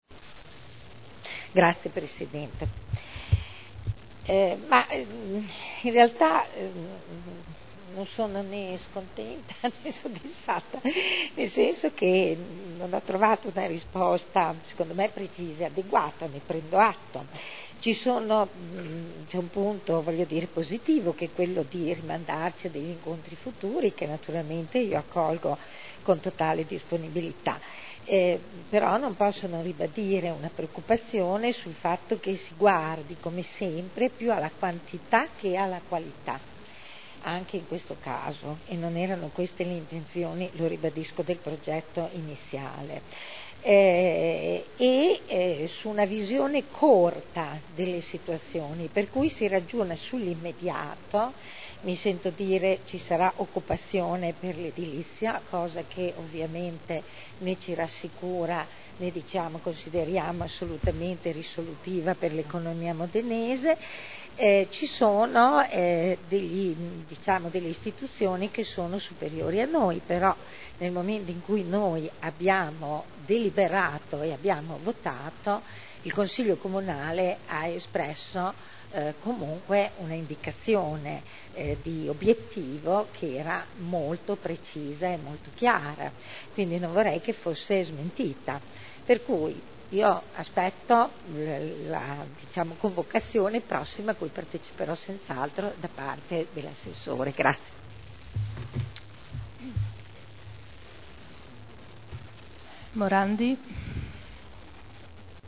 Seduta del 21/12/2009.